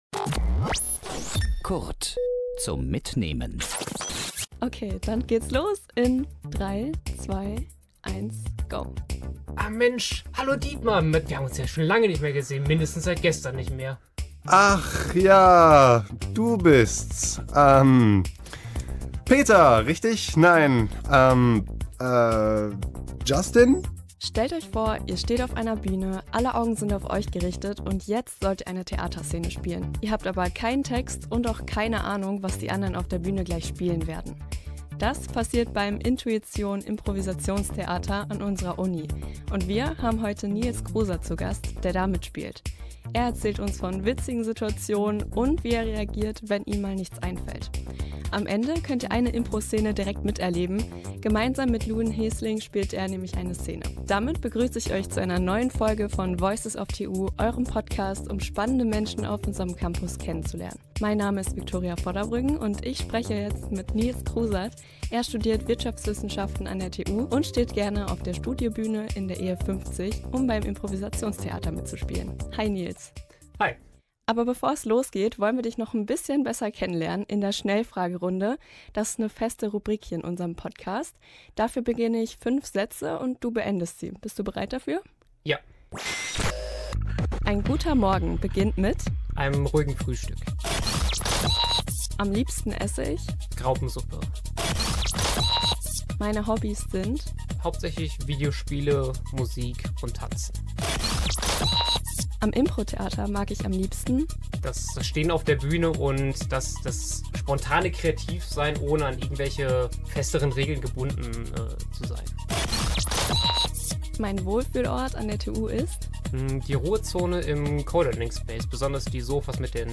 Beschreibung vor 11 Monaten Ohne Skript, ohne Plan, alles auf Anweisung - so läuft es beim "inTUition", dem Improvisationstheater an der TU Dortmund. Moderatorin
eine Szene vor dem Mikro